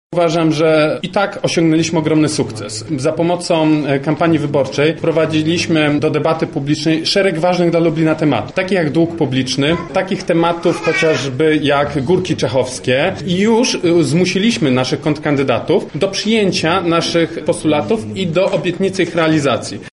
O kampanii mówi Jakub Kulesza z Kukiz 15′